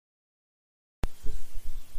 Free Foley sound effect: Stapler.
Stapler
Stapler is a free foley sound effect available for download in MP3 format.
086_stapler.mp3